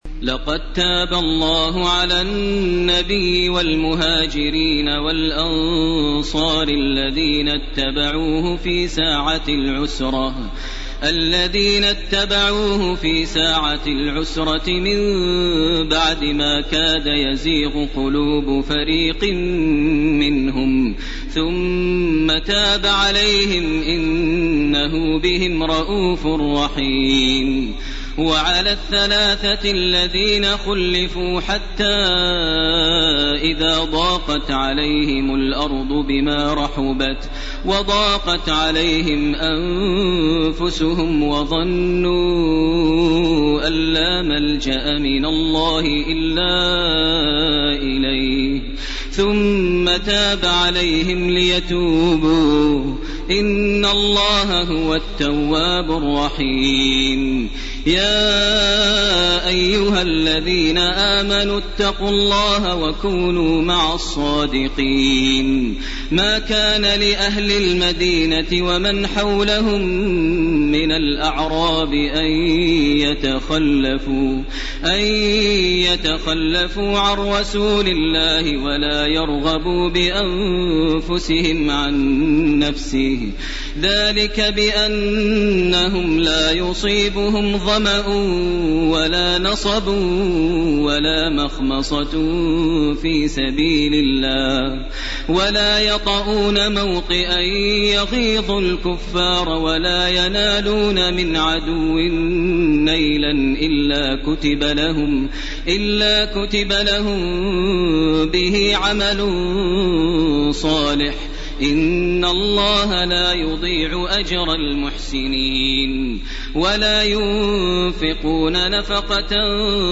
سورة التوبة 117 الي اخرها و سورة يونس 1- 24 > تراويح ١٤٢٩ > التراويح - تلاوات ماهر المعيقلي